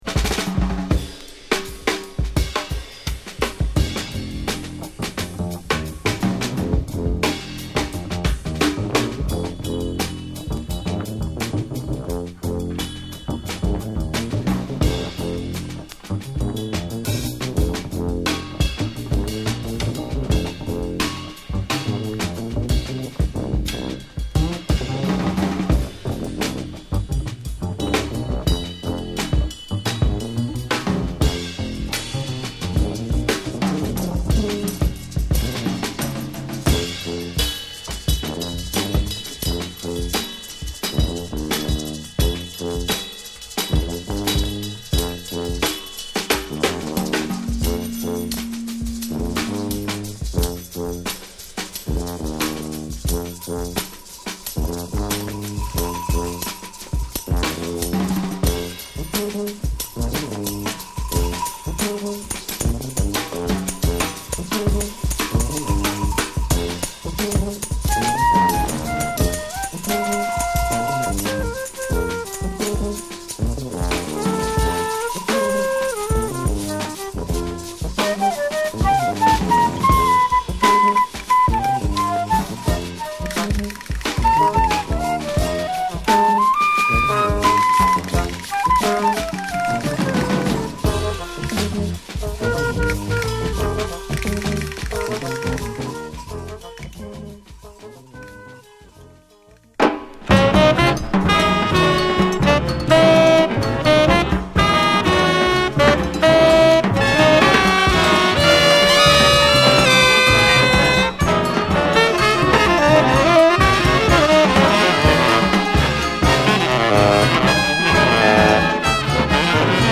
ブレイクから始まるドープ・ジャズ・ファンク！